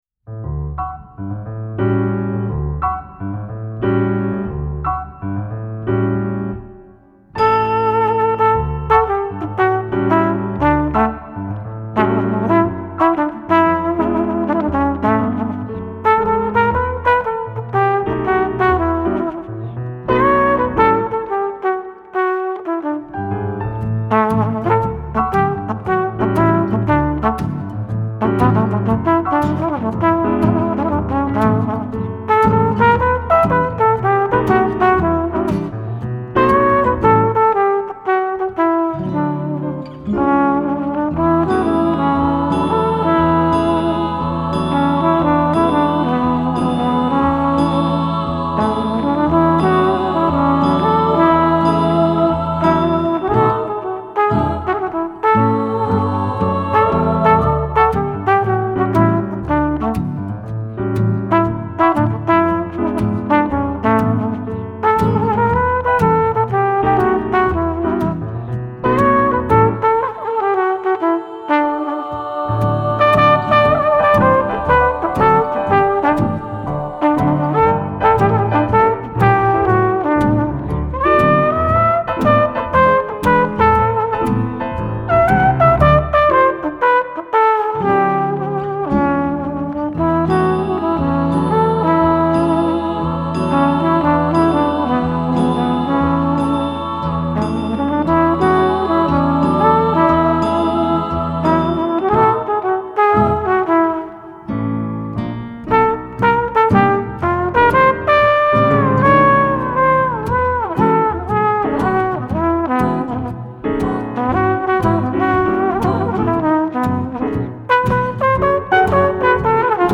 Genre : Jazz contemporain